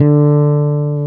Index of /90_sSampleCDs/Roland L-CDX-01/BS _Jazz Bass/BS _Warm Jazz